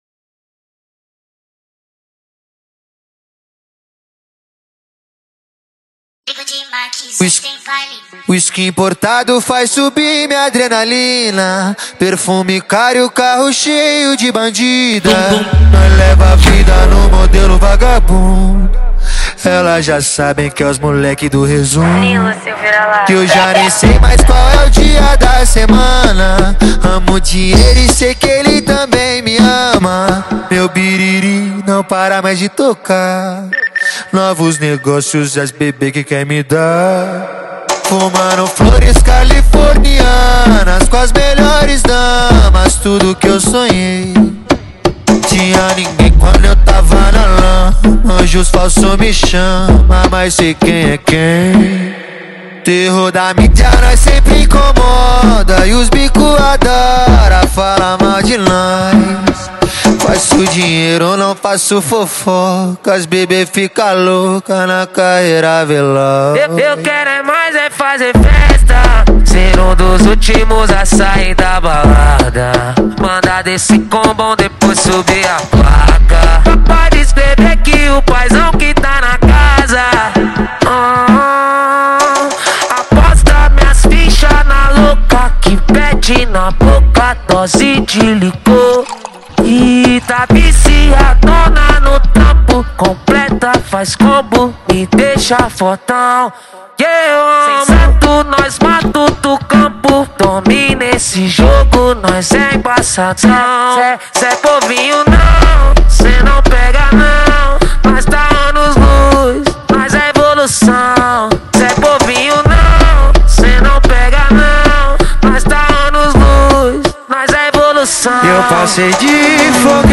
2024-12-19 01:08:13 Gênero: Funk Views